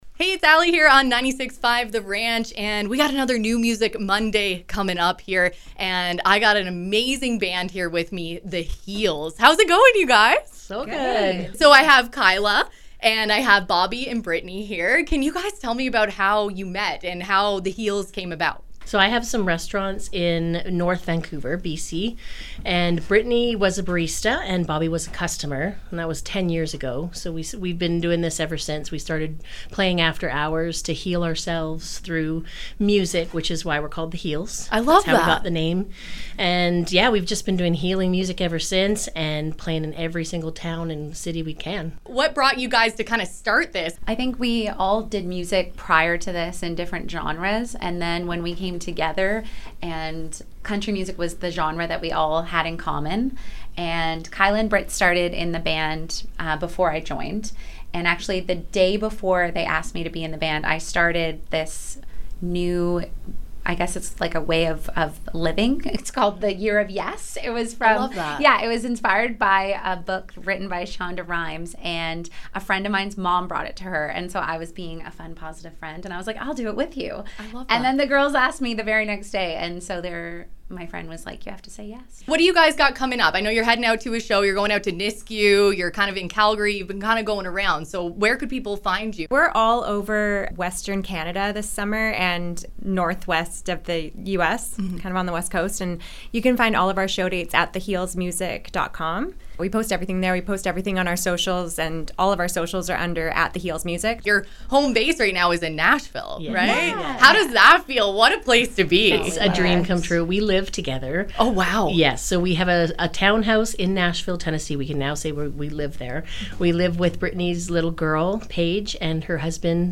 They have amazing harmony, and a bond like sisters.
The-Heels-Interview-For-Website.mp3